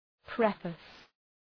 {‘prefıs}